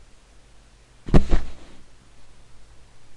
真正的战斗声音" fall4
描述：我做的一些战斗声......
Tag: 拳头 战斗 战斗 冲头 战斗